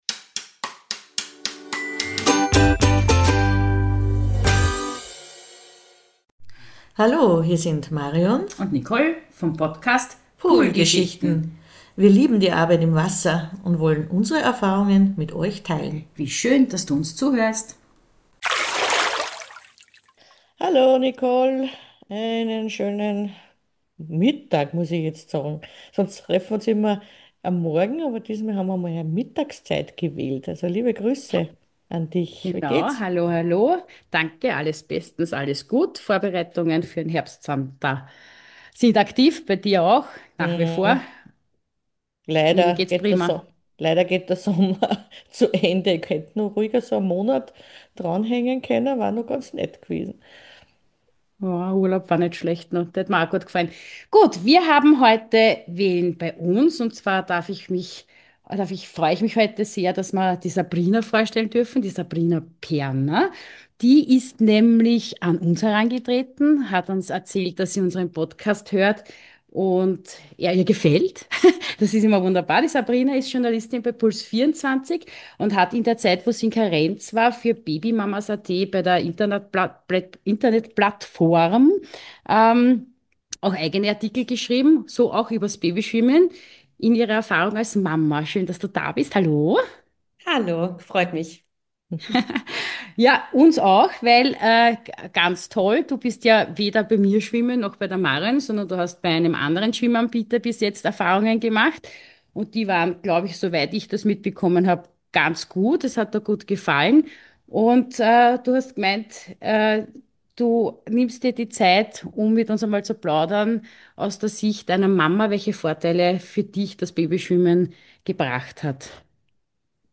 Poolgeschichte 28: Babyschwimmen: Nutzen & Erfahrungen aus erster Hand, erzählt von einer Mama ~ Poolgeschichten Podcast